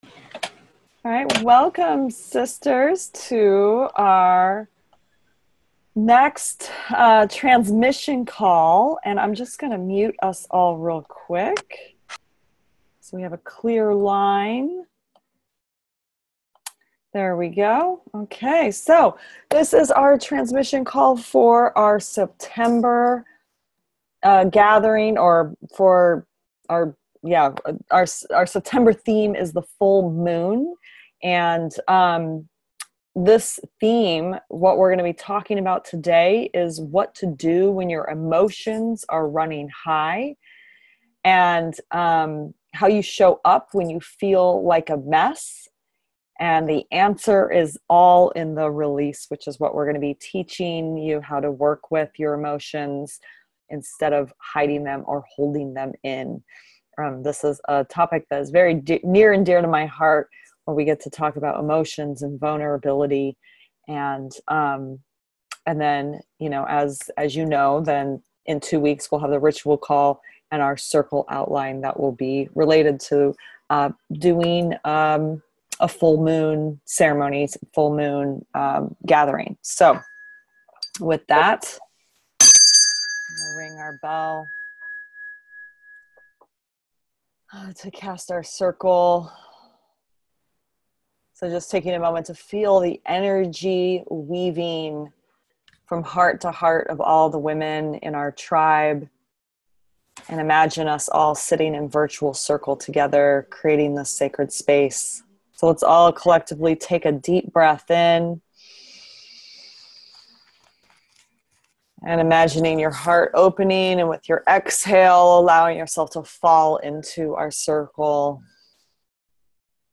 Wednesday’s transmission call was brimming with the energies of the full moon.